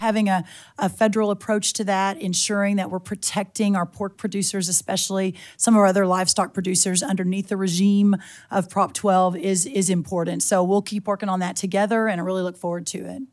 Iowa Congresswoman Ashley Hinson, a Republican from Marion, asked about the issue in a House budget hearing.